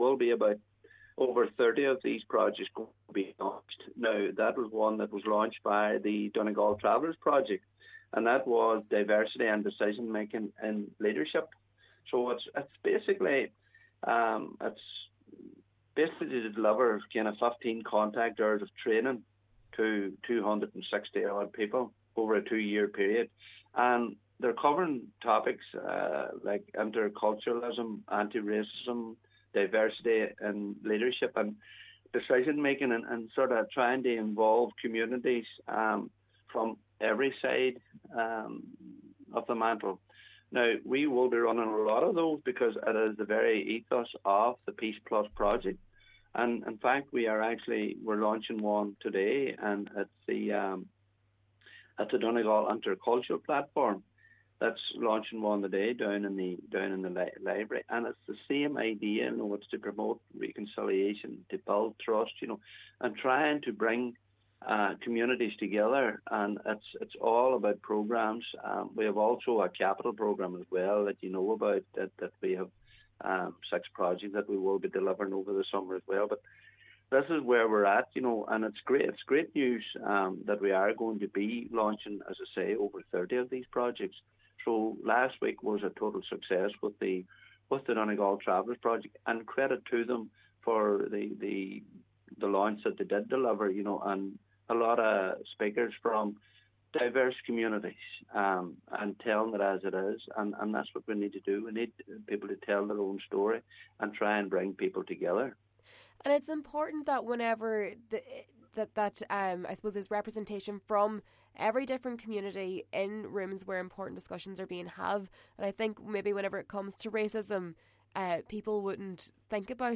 Councillor Paul Canning, Chair of the Donegal PEACEPLUS Partnership, says this is one project of many: